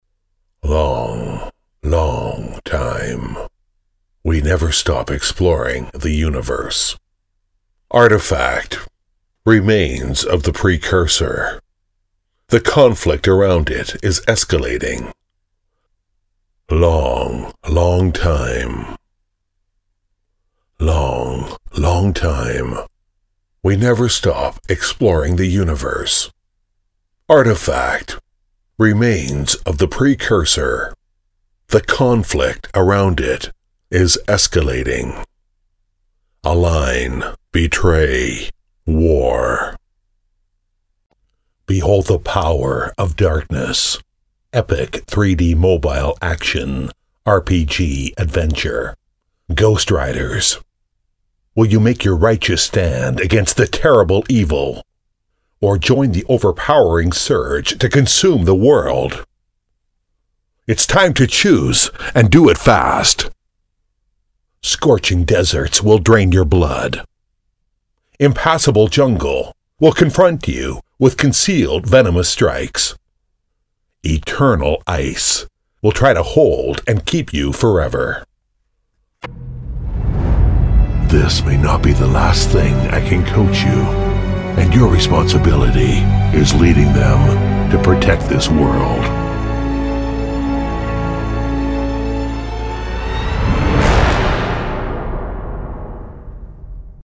• T110-1 美式英语 男声 游戏demo3 大气浑厚磁性|沉稳|低沉|神秘性感|素人